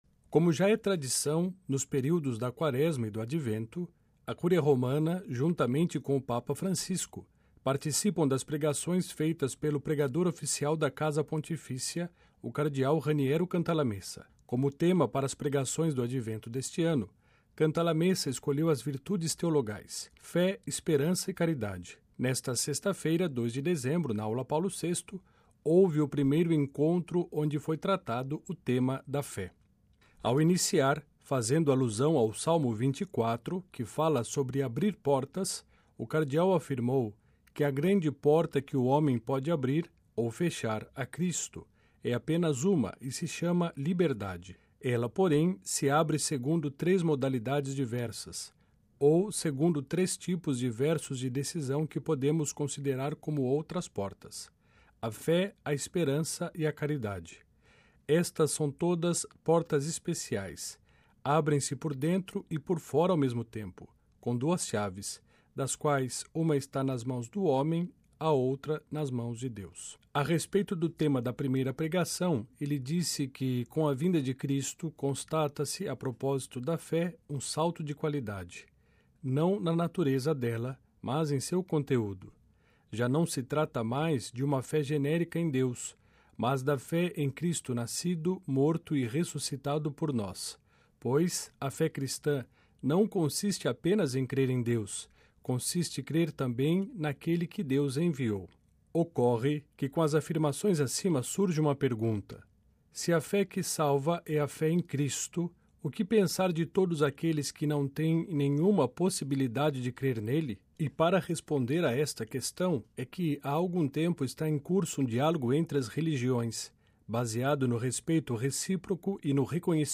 Na primeira das três pregações para a Cúria Romana, por ocasião do advento, o cardeal Raniero Cantalamessa, pregador da Casa Pontifícia tratou sobre o tema da fé.